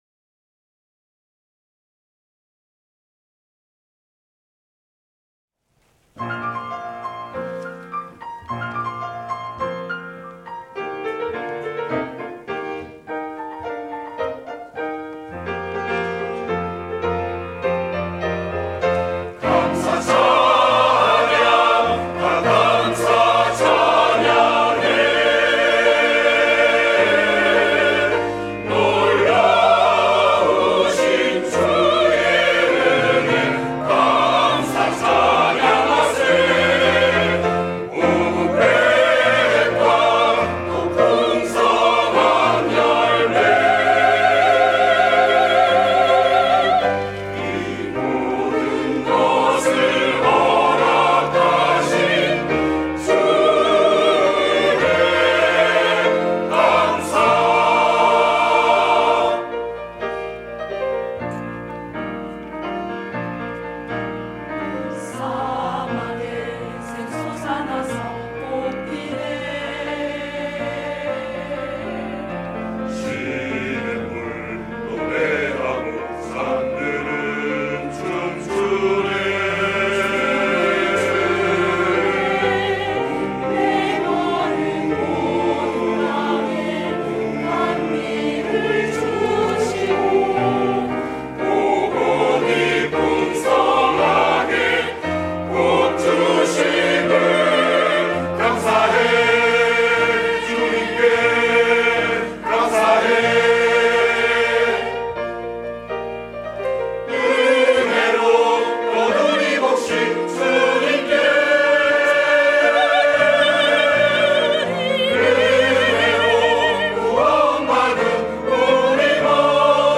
감사 찬양